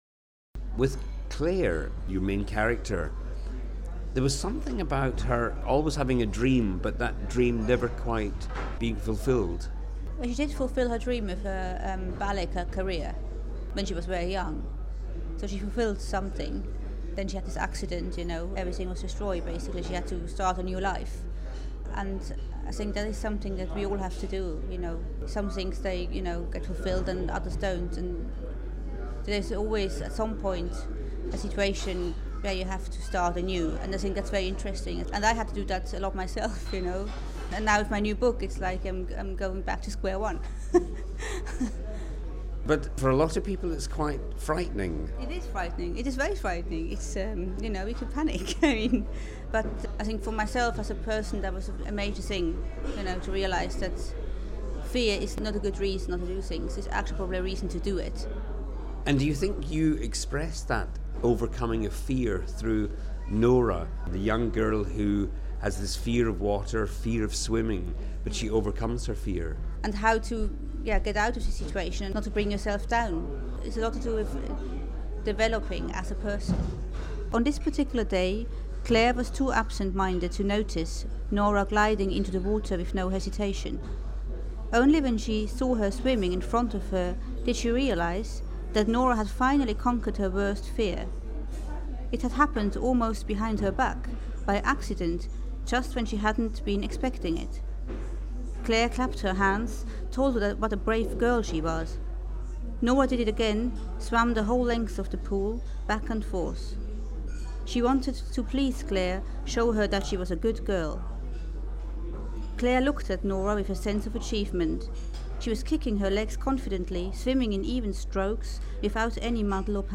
Interview with Zoë Jenny